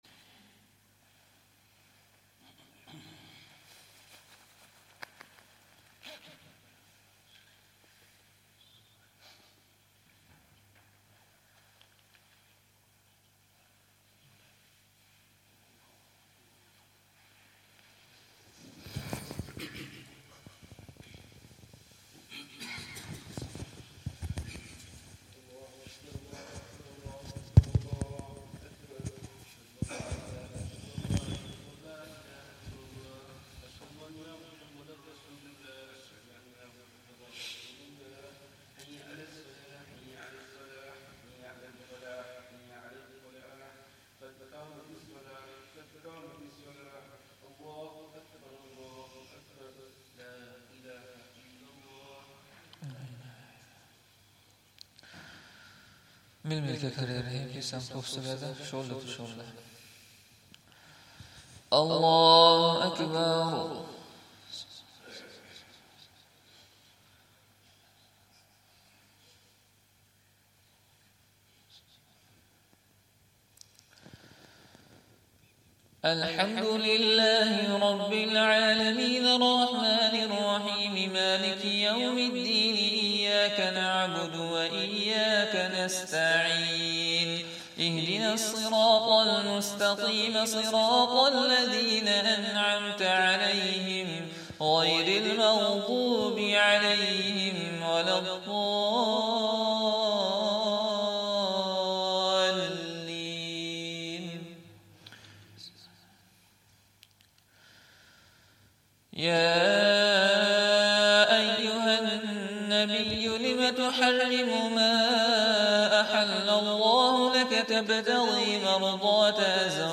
Fajr Salah
Zakariyya Jaam'e Masjid, Bolton